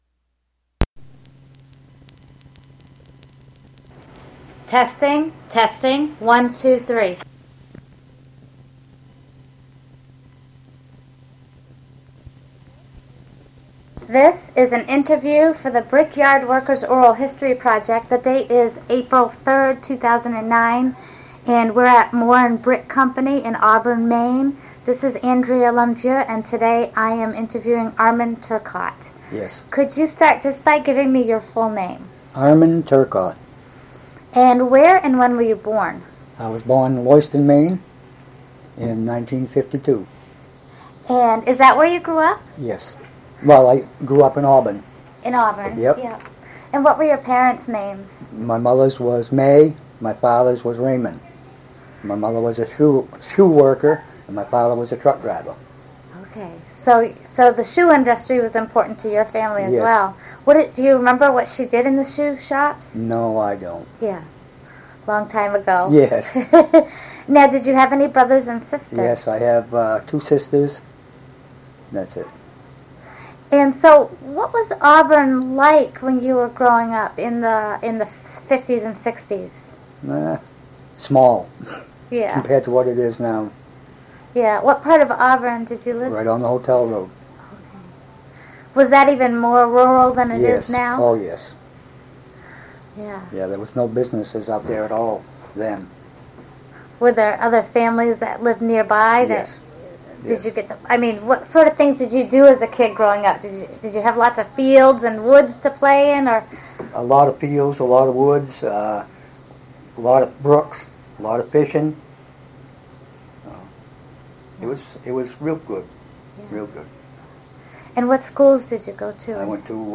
He went into the Morin Bricks and stayed there for the rest of his career, where he explains a lot of the process throughout the interview.